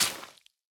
Minecraft Version Minecraft Version snapshot Latest Release | Latest Snapshot snapshot / assets / minecraft / sounds / block / sponge / wet_sponge / break4.ogg Compare With Compare With Latest Release | Latest Snapshot